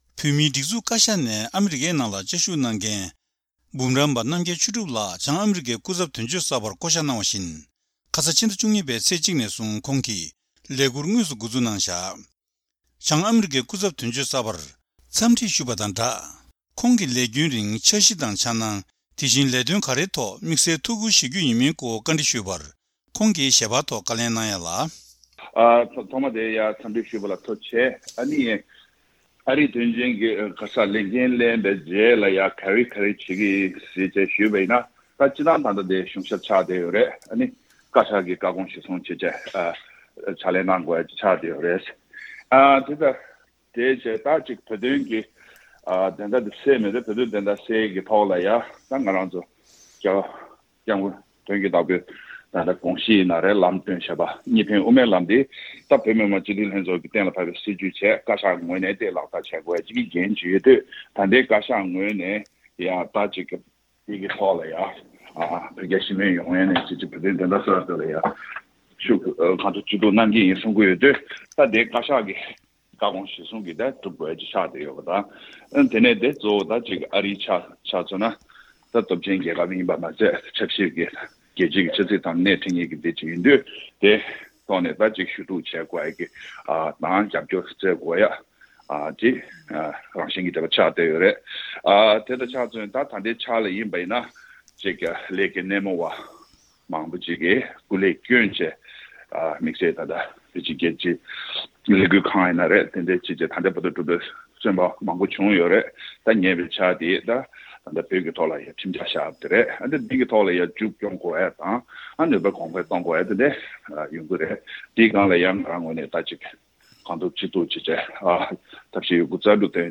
བྱང་ཨ་རིའི་སྐུ་ཚབ་དོན་གཅོད་གསར་པ་ལ་བཅར་འདྲི།
སྒྲ་ལྡན་གསར་འགྱུར། སྒྲ་ཕབ་ལེན།